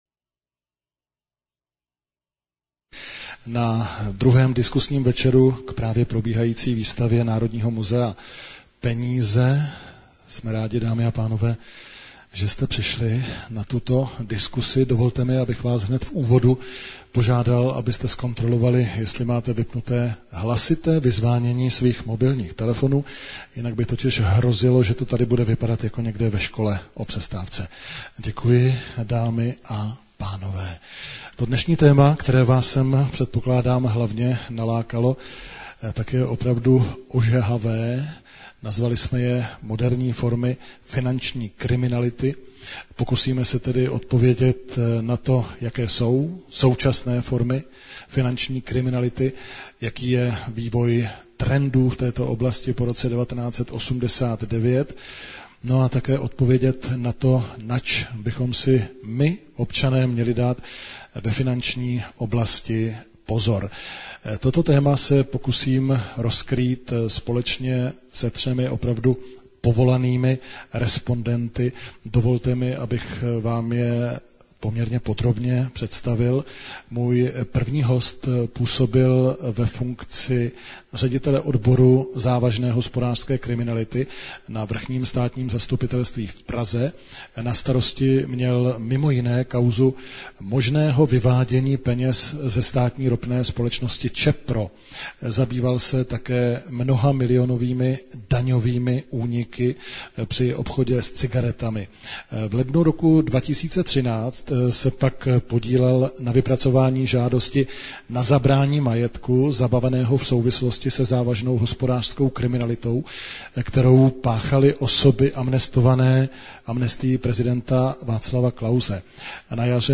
Audiozáznam z debaty o moderní finanční kriminalitě |